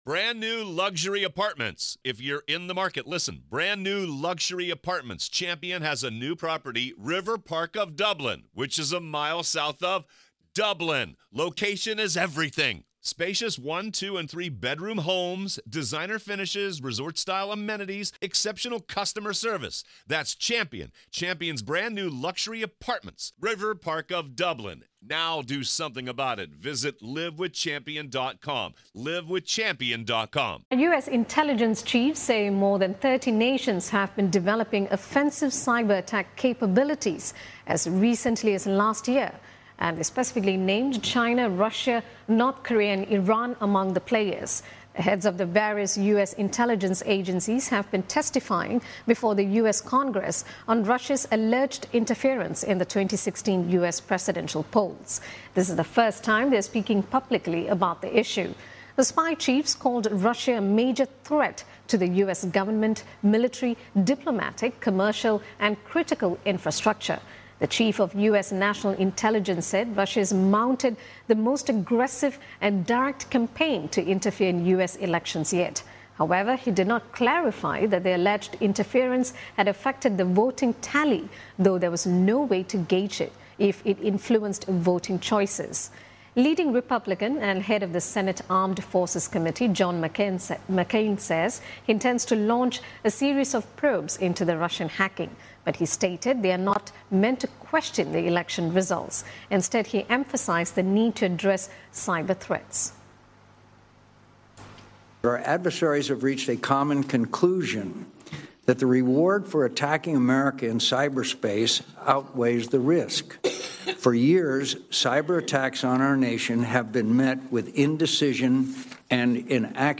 live update on Capitol Hill testimony from top US intelligence chiefs, via Channel NewsAsia.